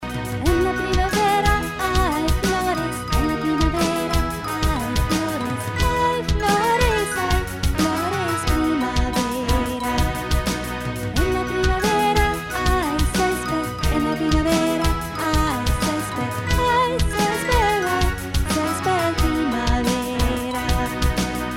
Spanish Song Lyrics and Sound Clip